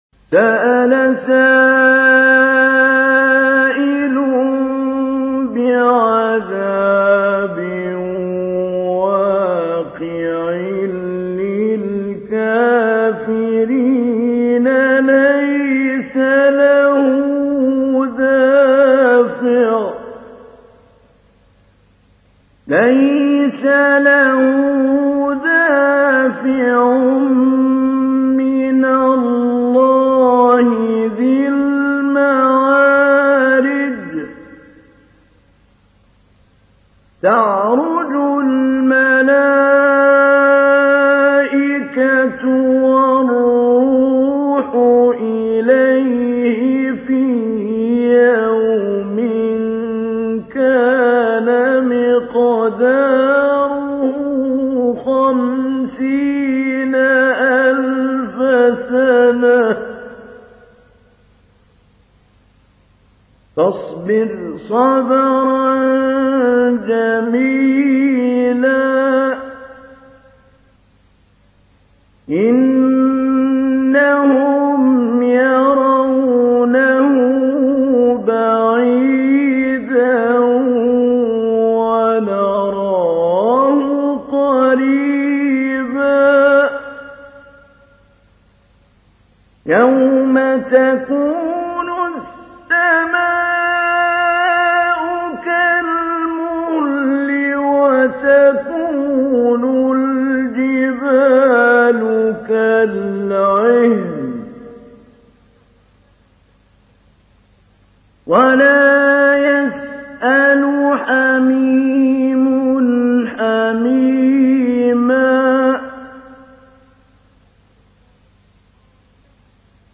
تحميل : 70. سورة المعارج / القارئ محمود علي البنا / القرآن الكريم / موقع يا حسين